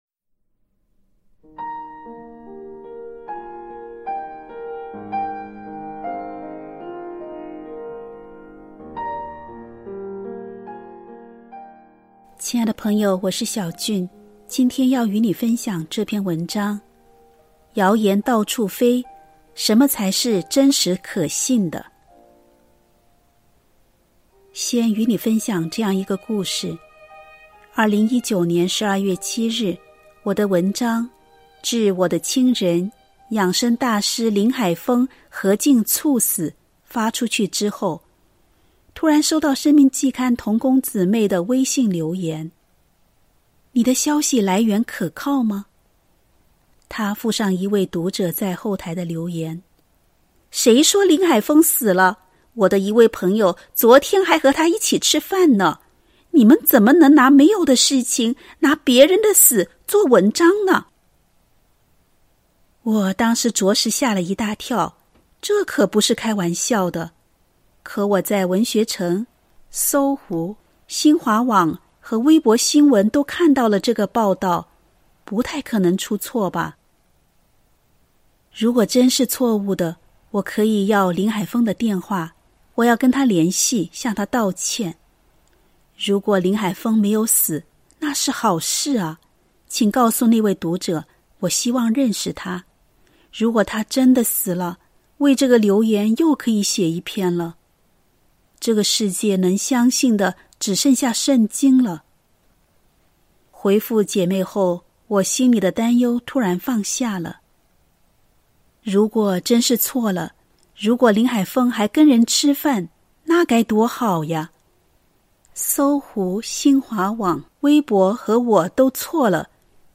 音频由作者本人朗读